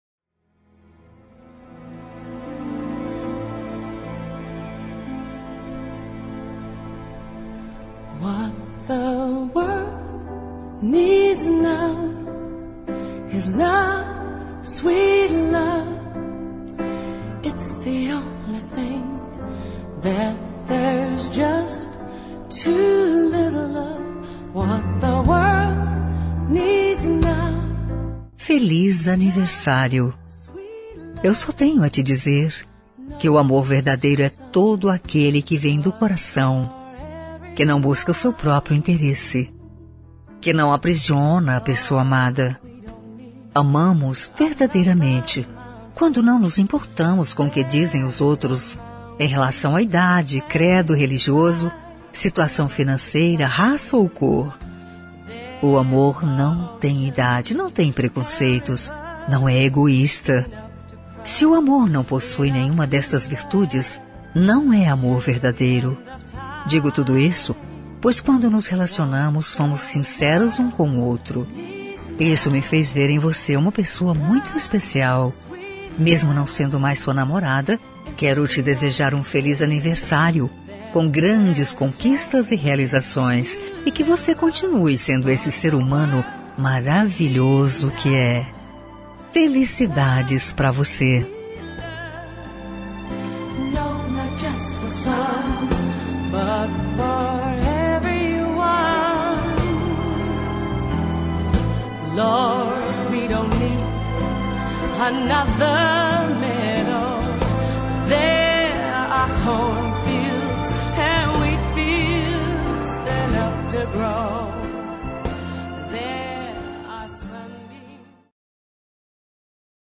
Telemensagem Aniversário de Ex. – Voz Feminina – Cód: 1355